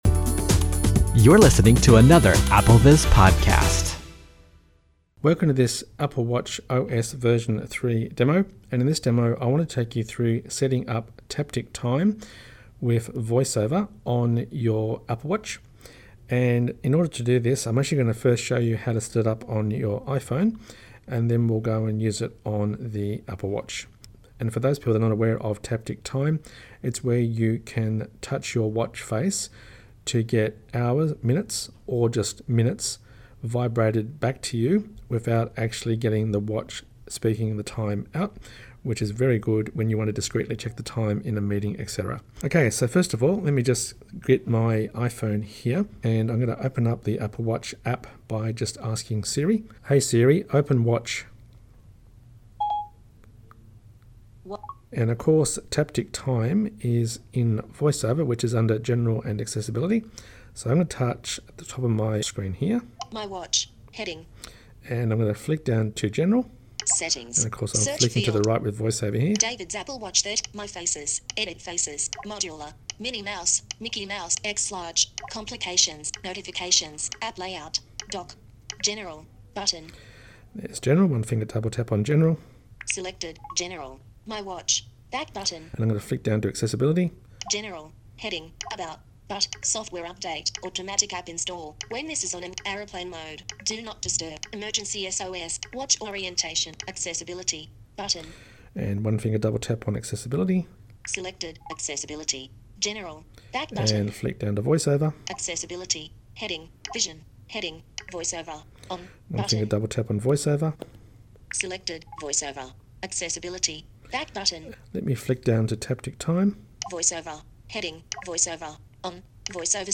A Demonstration of Sling Television on the 4th Generation Apple TV